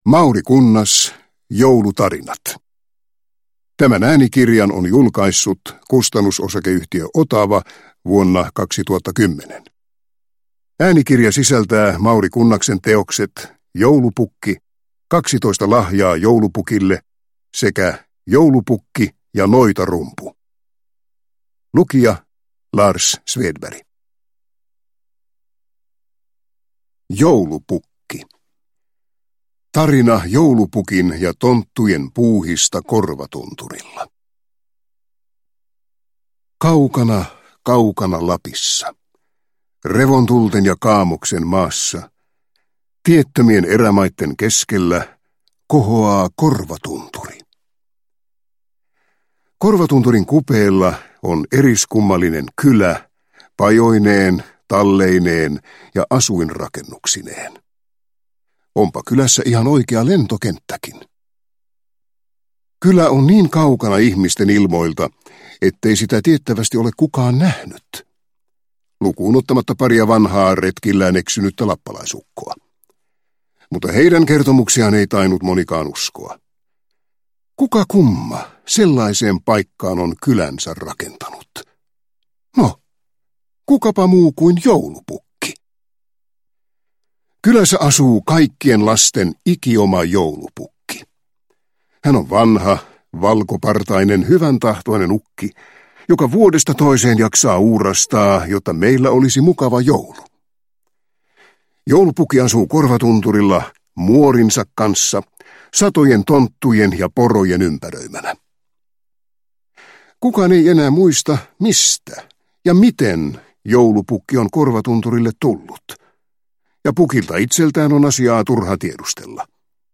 Joulutarinat – Ljudbok – Laddas ner